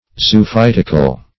Search Result for " zoophytical" : The Collaborative International Dictionary of English v.0.48: Zoophytic \Zo`o*phyt"ic\, Zoophytical \Zo`o*phyt"ic*al\, a. [Cf. F. zoophytique.]
zoophytical.mp3